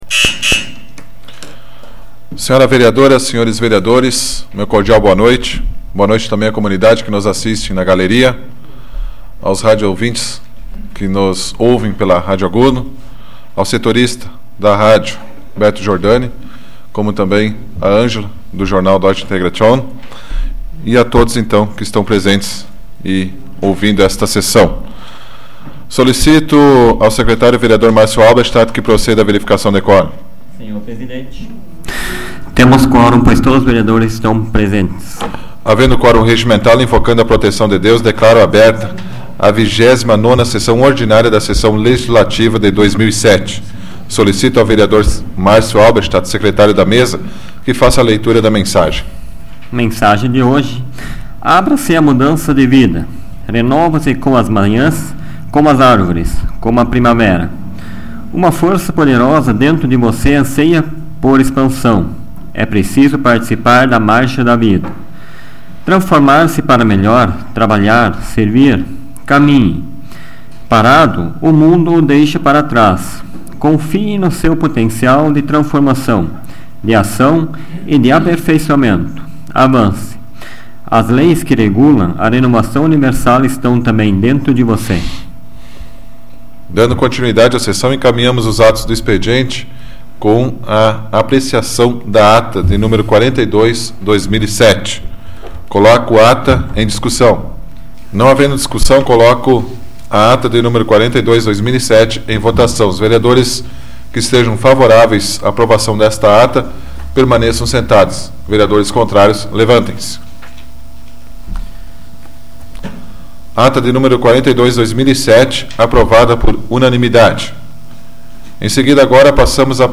Áudio da 103ª Sessão Plenária Ordinária da 12ª Legislatura, de 22 de outubro de 2007